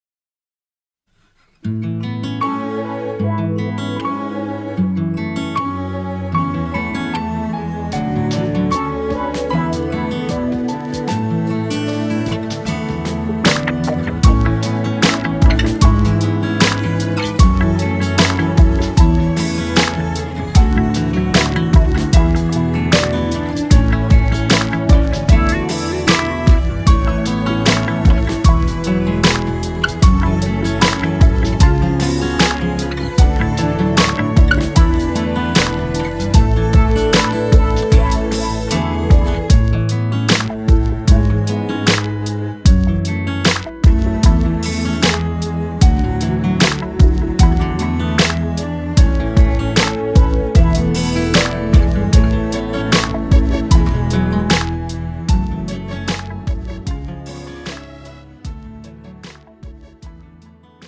MR 고음질 반주 다운로드.